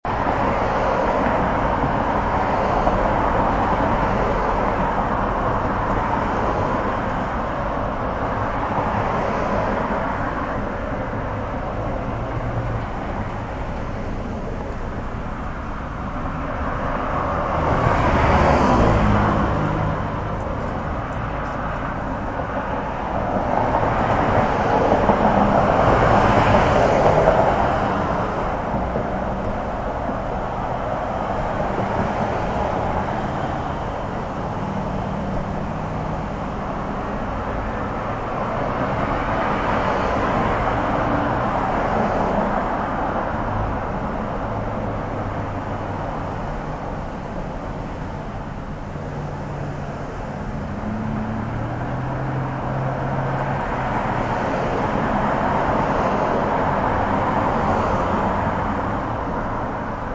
Note that the samples are mp3 (lower quality than the CD) to keep download times short.
So here you are, at the side of the road, trying to catch some z’s before setting out again. And as you rest, so close that you can almost touch them, your fellow travelers continue on, tires humming and engines purring, back and forth to unknown destinations. As you listen carefully, you can hear that they move with varying tempos, sometimes in groups, sometimes apart.